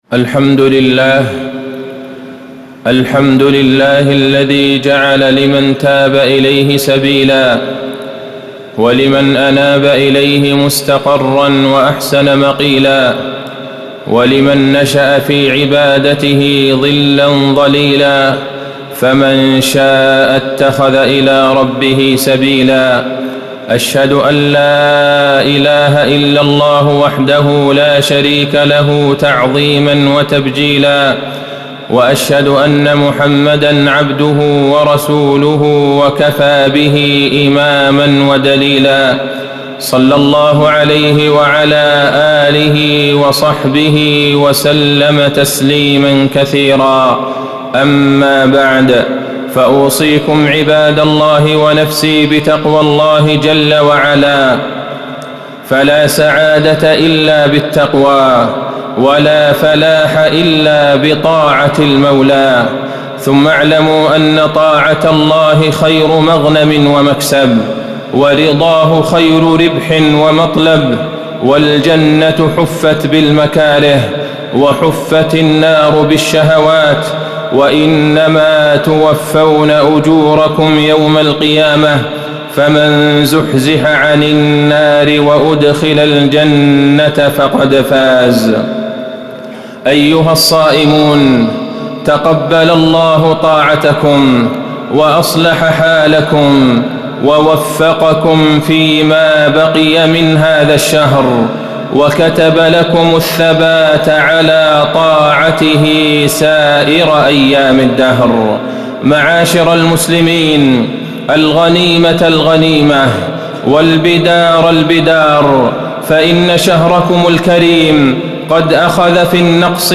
تاريخ النشر ١٧ رمضان ١٤٣٩ هـ المكان: المسجد النبوي الشيخ: فضيلة الشيخ د. عبدالله بن عبدالرحمن البعيجان فضيلة الشيخ د. عبدالله بن عبدالرحمن البعيجان العشر الأواخر والاعتكاف